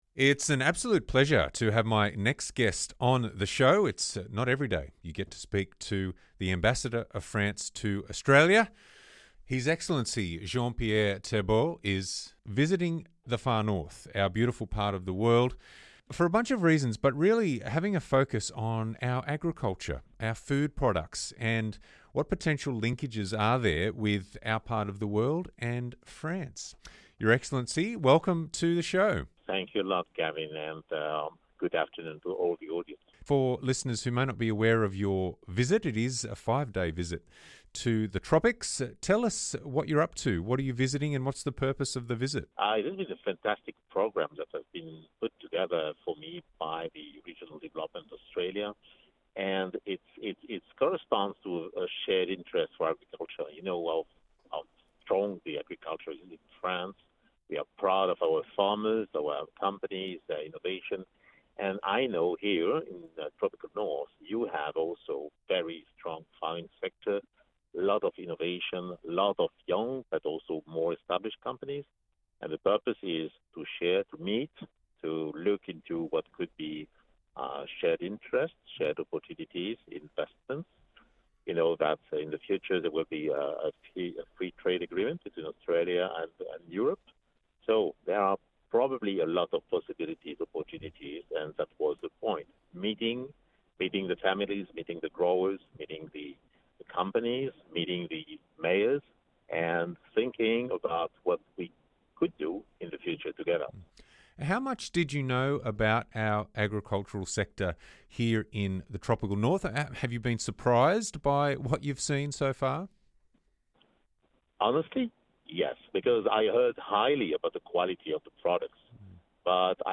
the French Ambassador to Australia for a chat about his visit to the Tropical North and the potential for exports of our premium produce to France